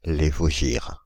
Prononciation
France (Île-de-France): IPA: /le.vɔ.ʒiʁ/